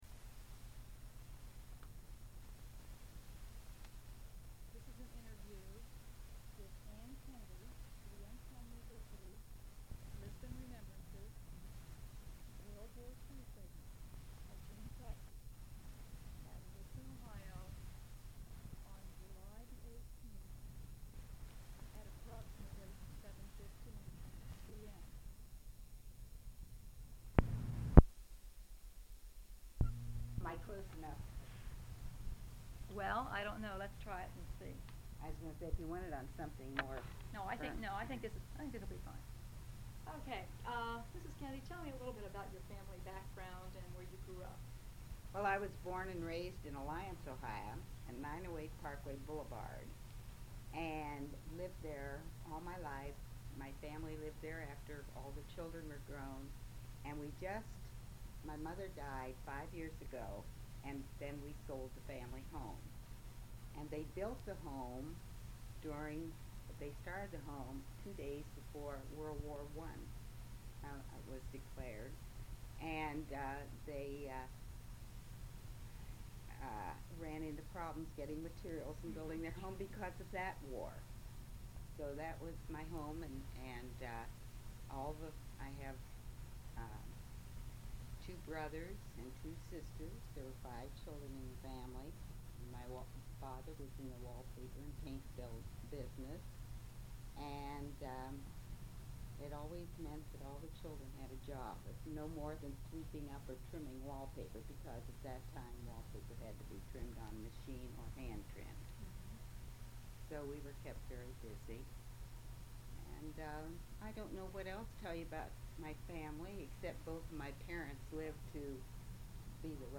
Transcript of interview taped on June 18, 1989.